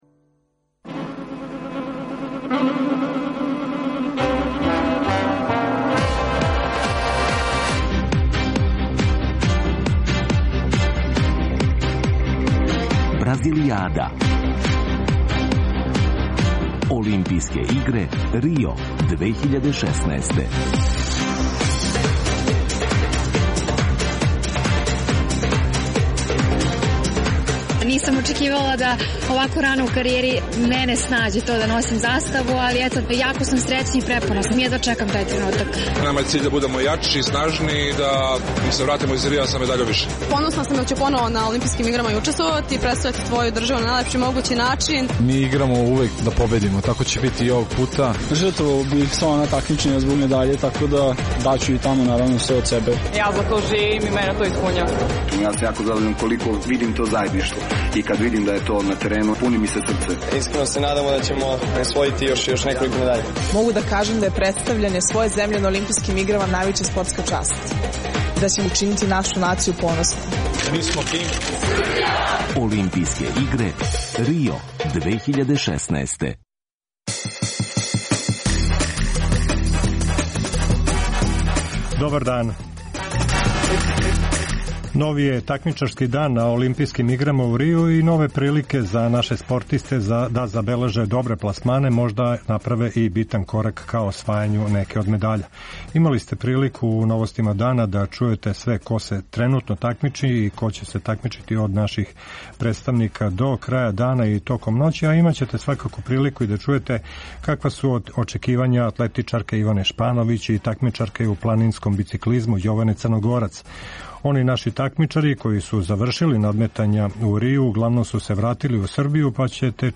Специјална емисија која ће се емитовати током трајања Олимпијских игара у Рију. Пратимо наше спортисте који учествују на ОИ, анализирамо мечеве, уз госте у Студију 1 Радио Београда и укључења наших репортера са лица места.